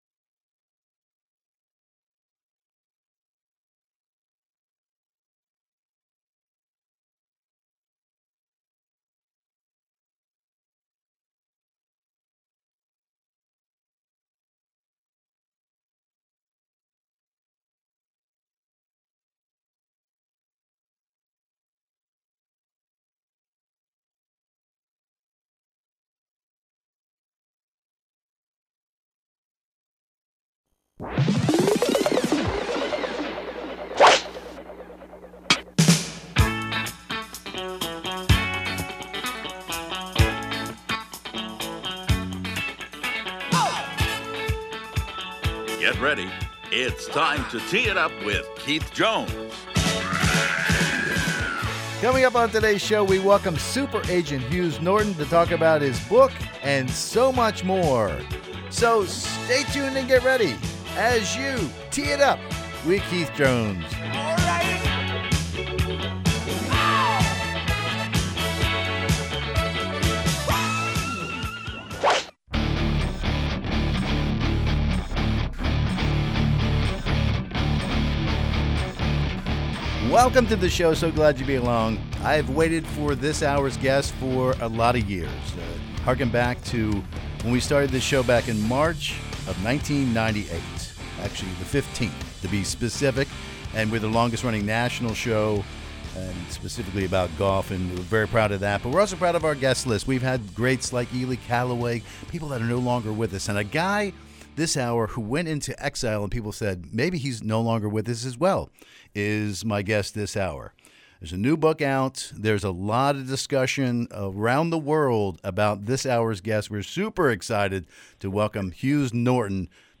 We peel back the layers in this in-depth interview.